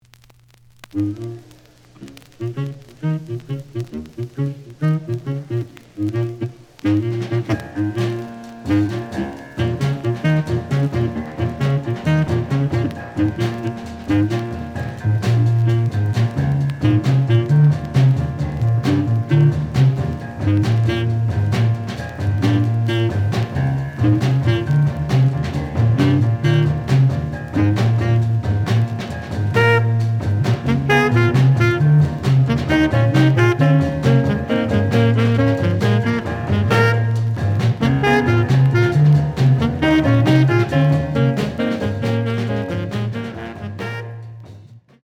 The audio sample is recorded from the actual item.
●Format: 7 inch
●Genre: Jazz Funk / Soul Jazz
Slight edge warp. But doesn't affect playing. Plays good.)